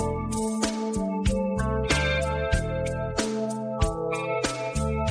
classic_rock_mellow_gold_progressive.mp3